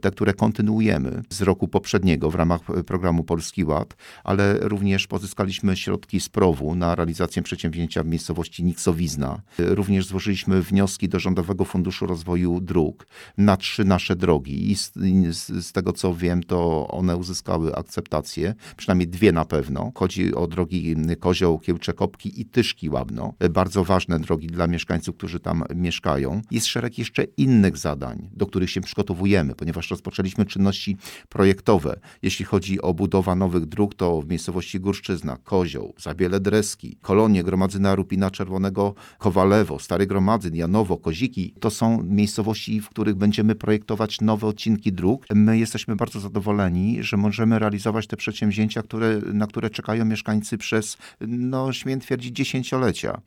– Jesteśmy bardzo nastawieni na rozwój – mówił w audycji Gość Dnia, wójt gminy Kolno Józef Wiśniewski.